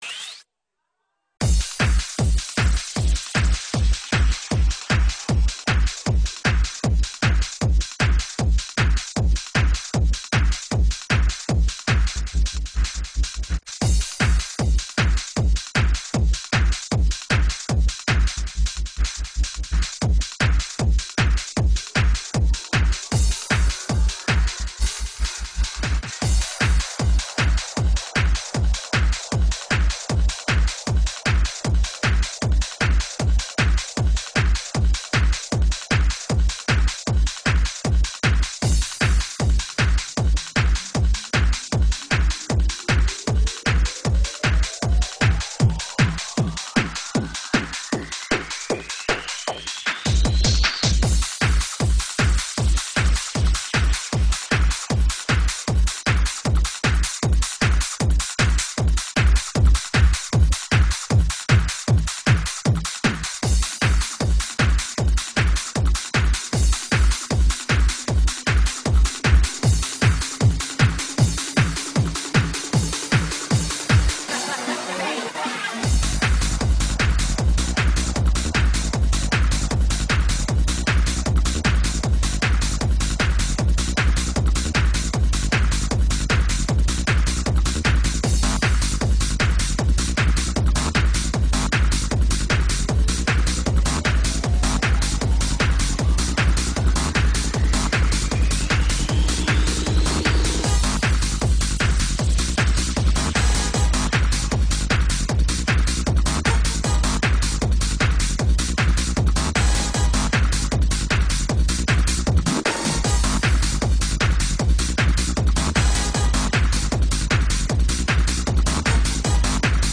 Hard House